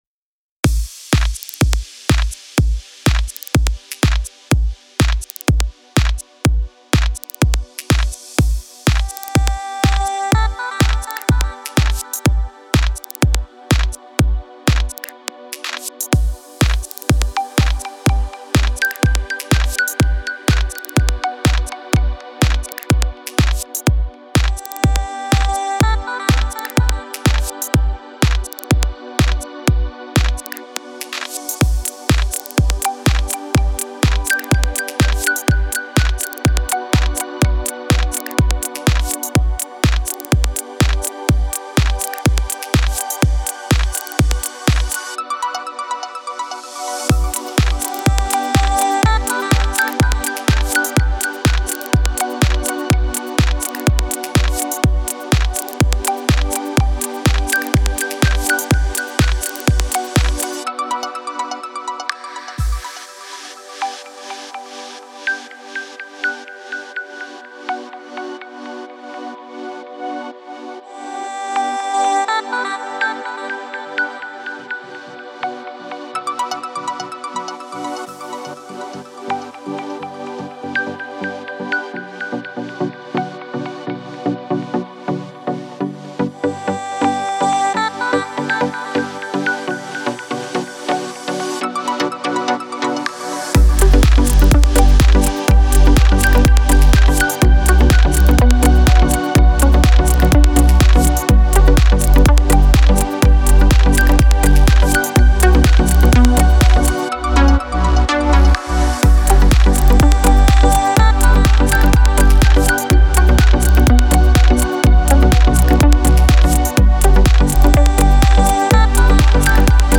Стиль: Progressive House / Melodic Progressive